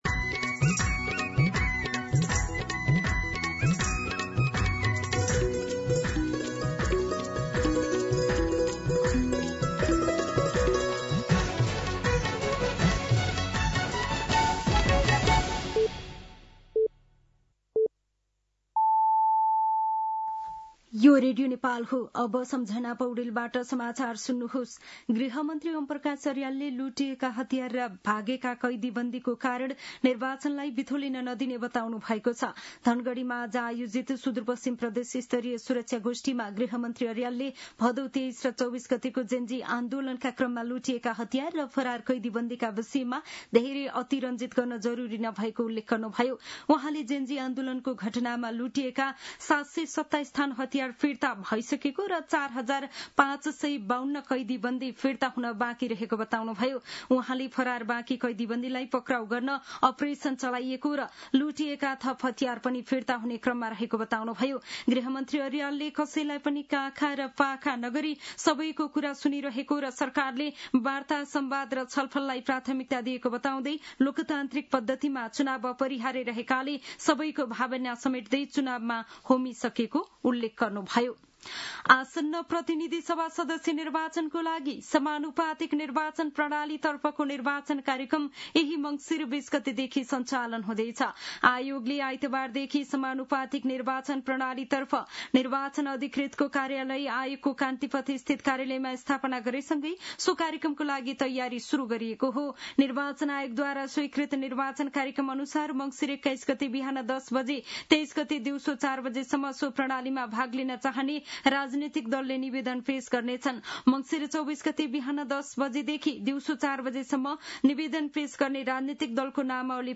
दिउँसो ४ बजेको नेपाली समाचार : १६ मंसिर , २०८२
4-pm-News-8-16.mp3